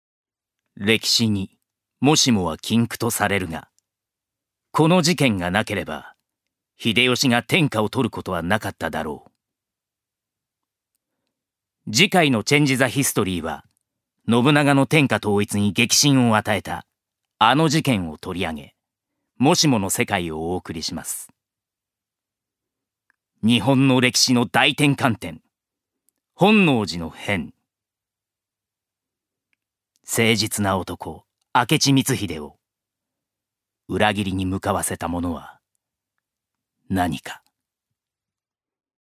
ジュニア：男性
音声サンプル
ナレーション１